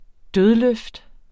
Udtale [ ˈdøð- ]